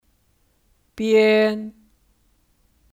边 (Biān 边)